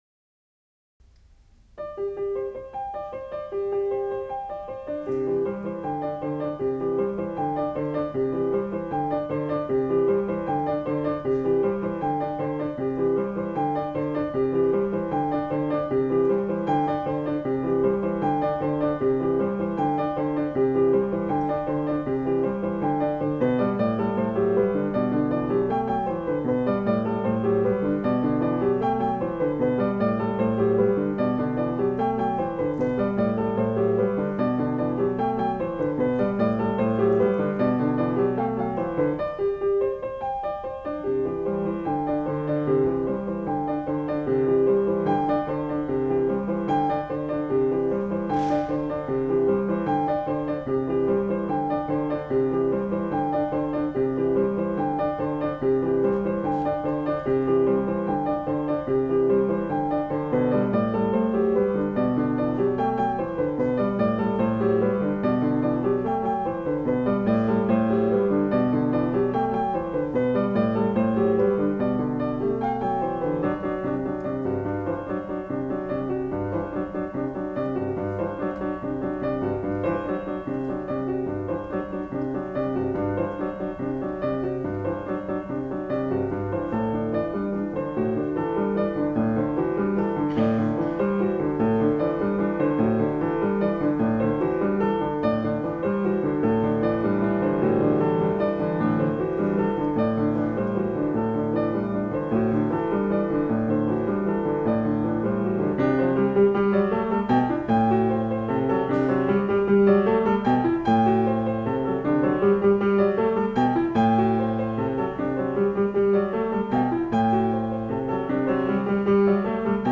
piano solo; computer-aided.